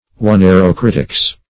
Search Result for " oneirocritics" : The Collaborative International Dictionary of English v.0.48: Oneirocriticism \O*nei`ro*crit`i*cism\, Oneirocritics \O*nei`ro*crit`ics\, n. The art of interpreting dreams.
oneirocritics.mp3